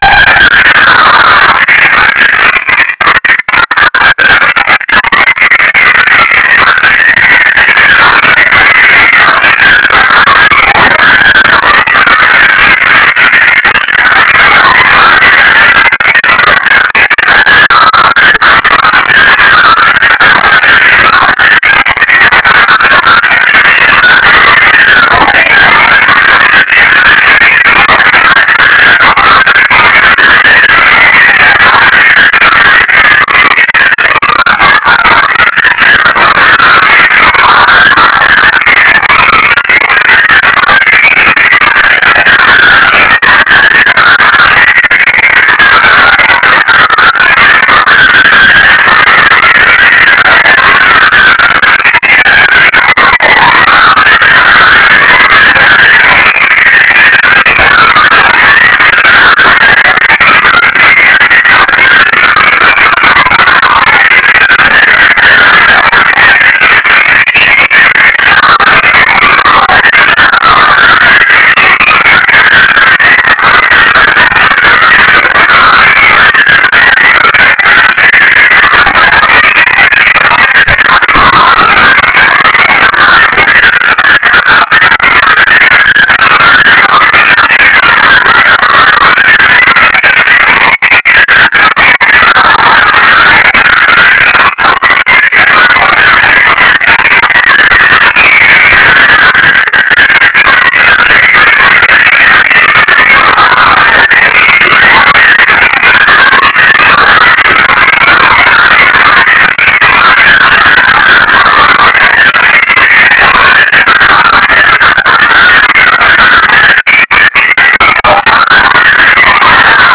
Seduta Aula 242 - 14 gennaio 1998
Dichiarazioni programmatiche del
presidente della Giunta
Palomba_VI.ra